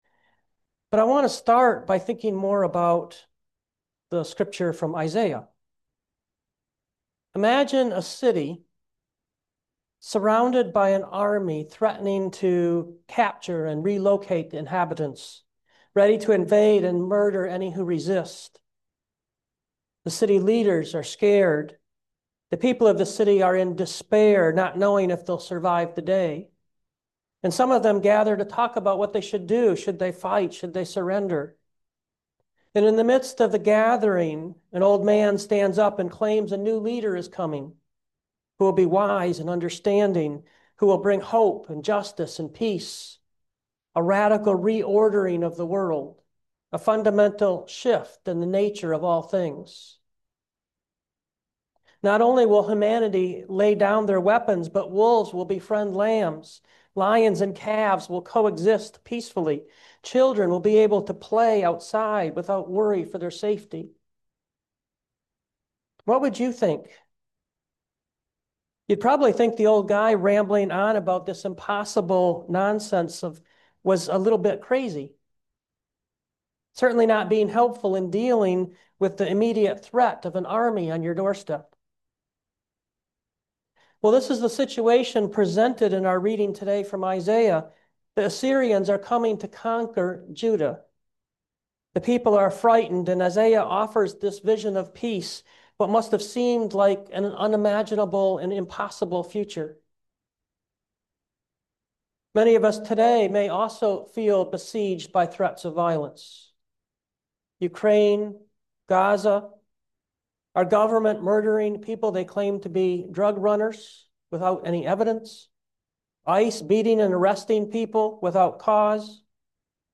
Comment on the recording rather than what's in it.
Note: For the gospel, we heard a re-imagining of the Angel’s visit to Mary and Joseph: